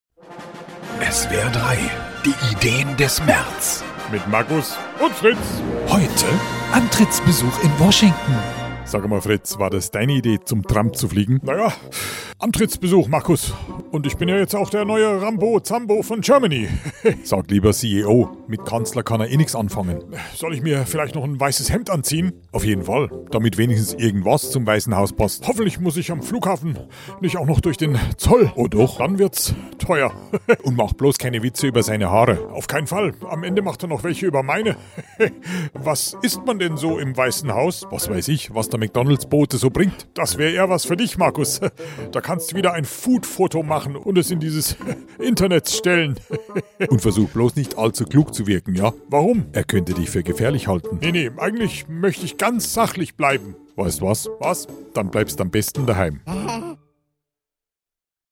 SWR3 Comedy Die Ideen des Merz: Antrittsbesuch in Washington